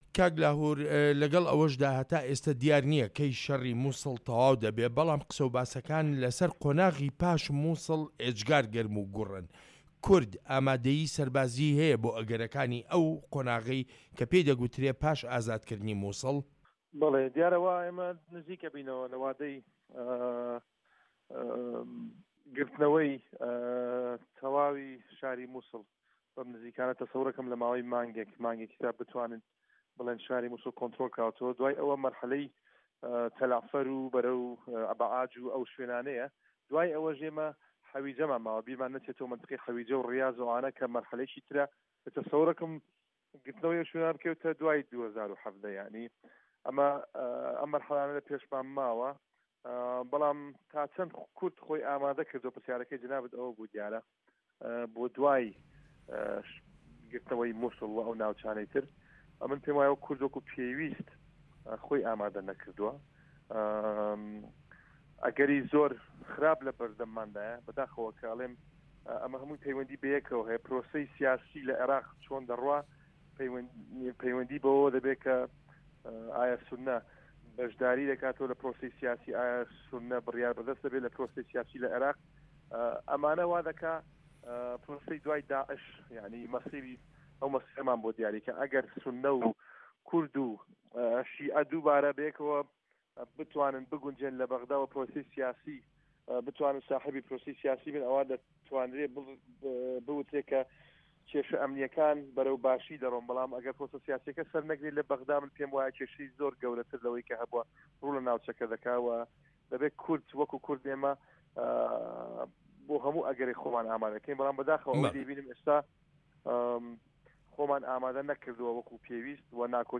وتووێژ لەگەڵ لاهور تاڵەبانی